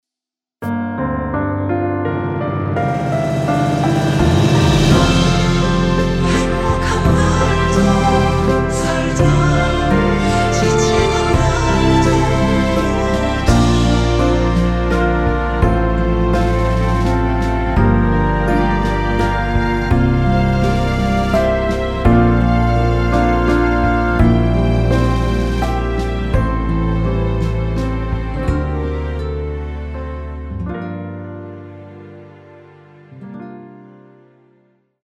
이곡의 코러스는 미리듣기에 나오는 부분이 전부 입니다.다른 부분에는 코러스가 없습니다.(미리듣기 확인)
원키에서(-3)내린 코러스 포함된 MR입니다.
앞부분30초, 뒷부분30초씩 편집해서 올려 드리고 있습니다.